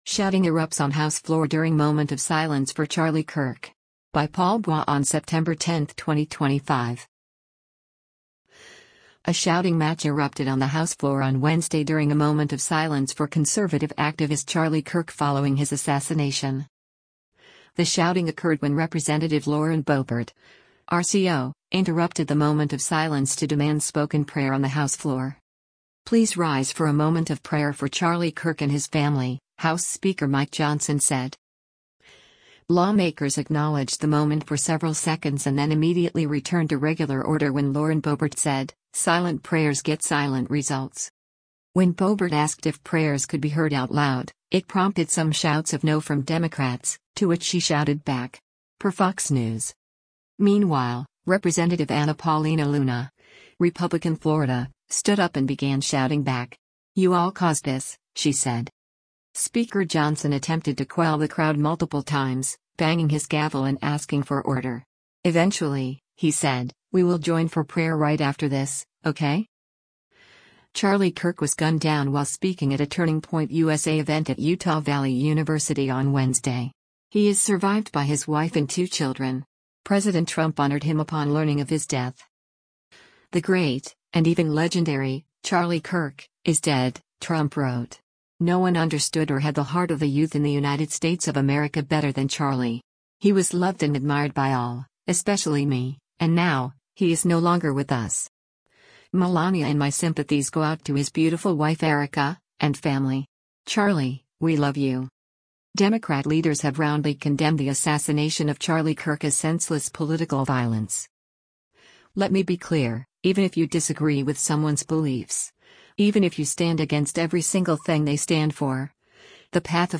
A shouting match erupted on the House floor on Wednesday during a moment of silence for conservative activist Charlie Kirk following his assassination.
When Boebert asked if prayers could be heard “out loud,” it prompted some shouts of “no” from Democrats, to which she shouted back.
Speaker Johnson attempted to quell the crowd multiple times, banging his gavel and asking for order.